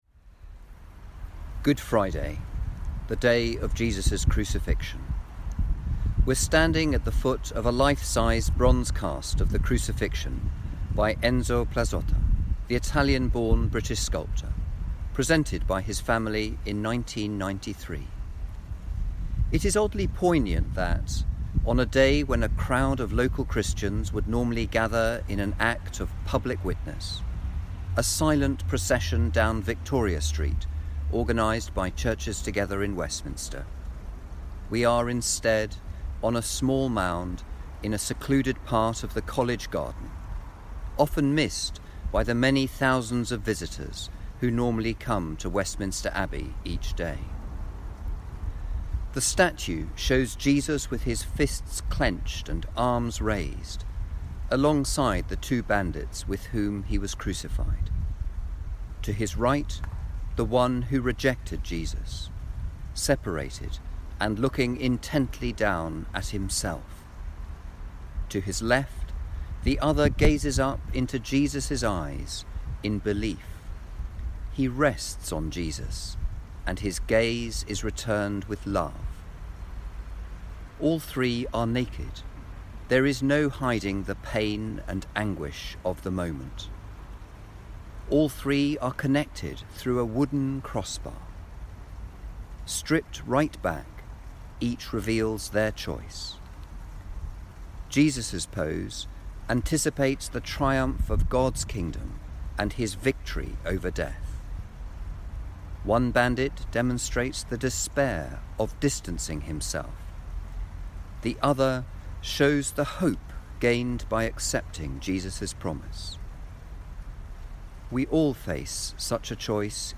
Recorded in College Garden within the walls of the Abbey, at the crucifix by the Italian sculptor, Enzo Plazzotta.
Music: Performed by the Choir of Westminster Abbey
This podcast was recorded by members of the Westminster Abbey community who are currently in self-isolation in the Abbey precincts.